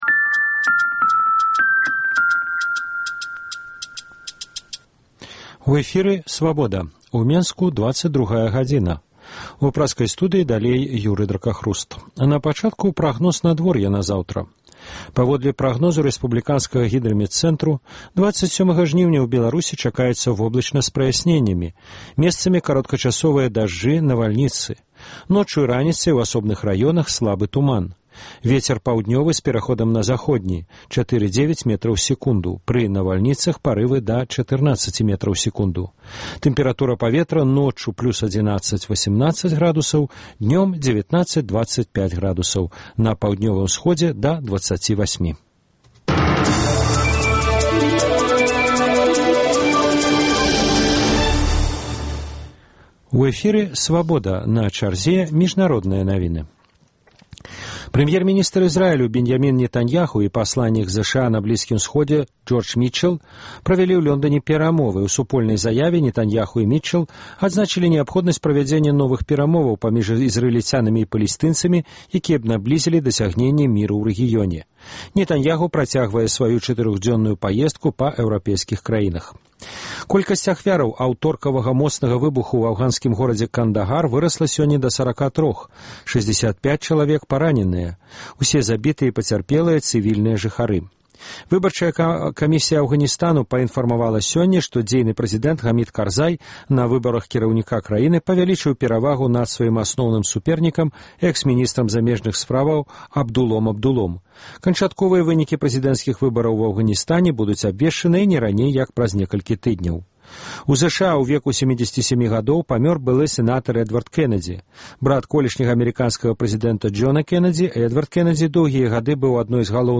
Вечаровы госьць, сацыяльныя досьледы, галасы людзей